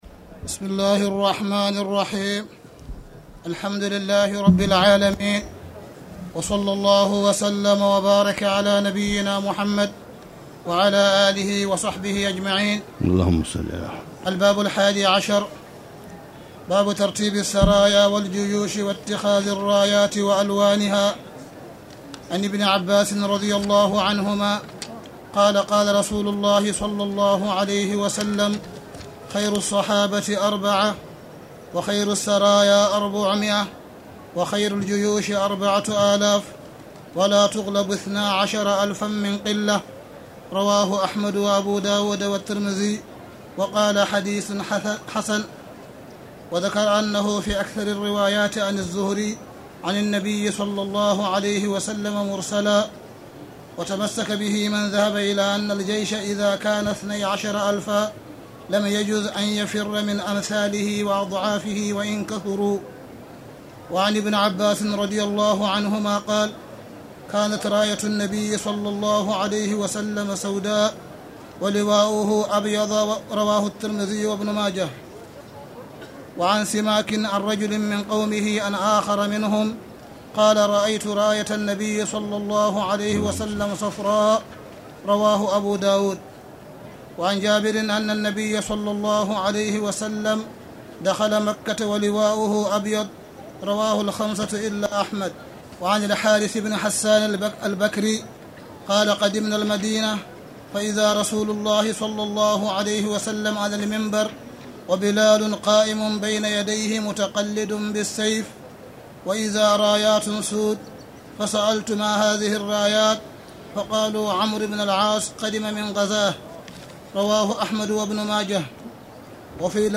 تاريخ النشر ٢ رمضان ١٤٣٧ هـ المكان: المسجد الحرام الشيخ: معالي الشيخ أ.د. صالح بن عبدالله بن حميد معالي الشيخ أ.د. صالح بن عبدالله بن حميد نيل الاوطار كتاب الجهاد (1)